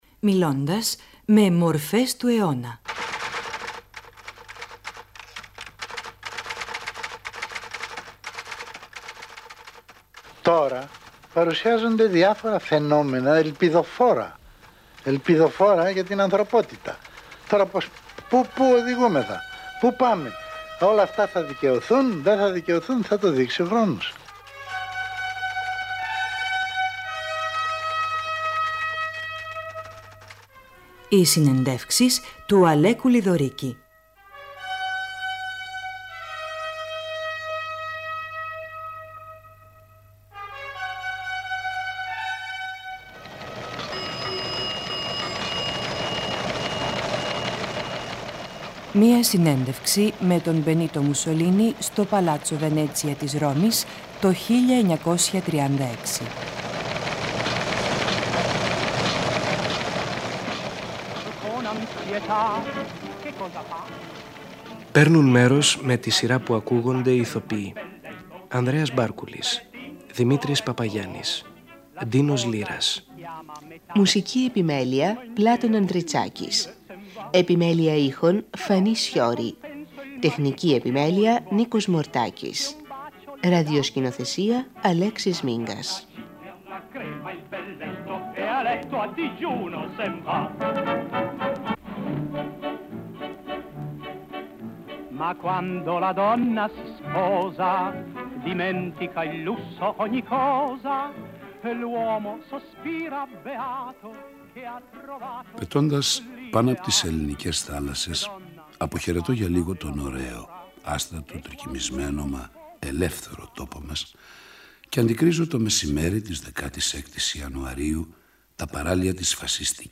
Παίρνουν μέρος οι ηθοποιοί